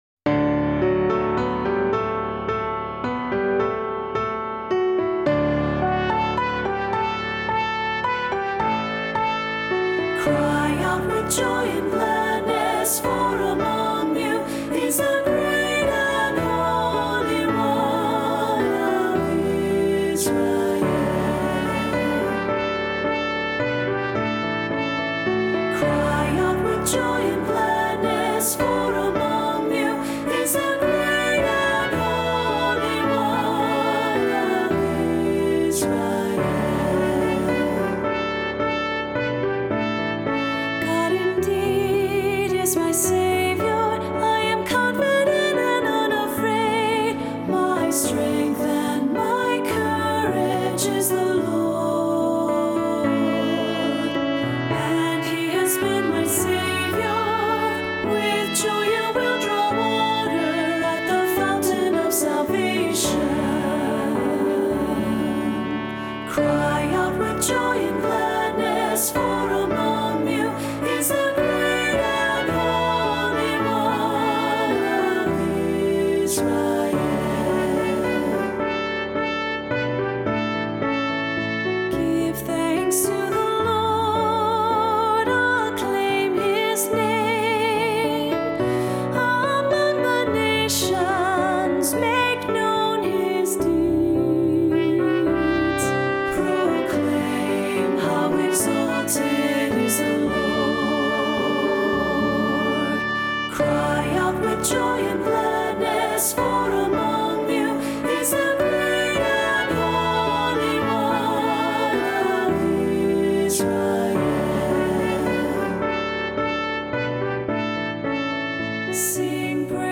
Voicing: Assembly,SAT